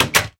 door_close.ogg